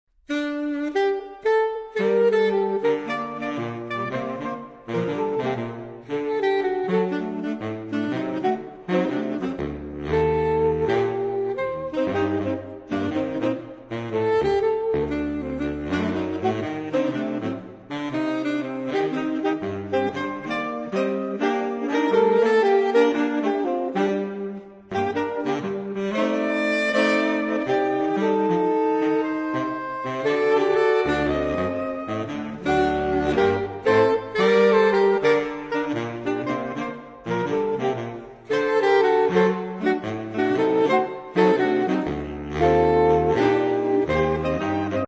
4 Saxophone (SATBar/AATBar)